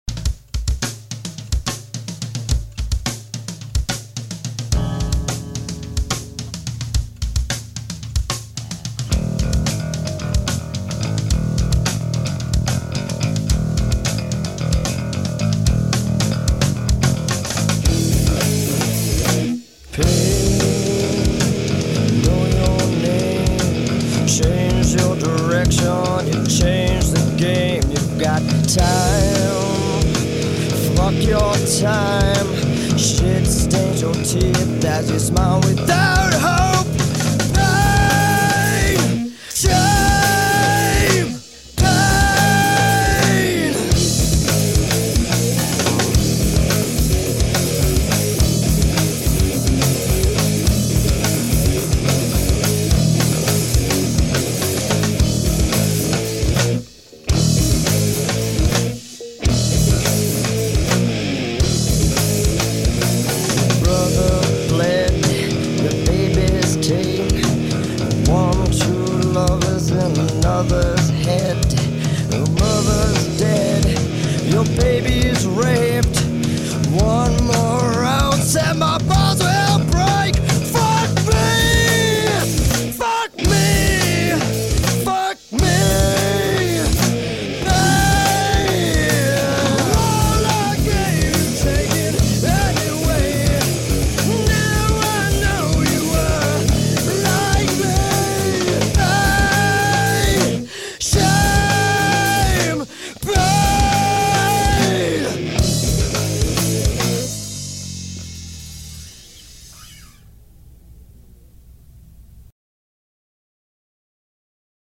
From 1994-1997 I was in an Orange County, CA based rock band, UNLEDED.
vocals
Guitar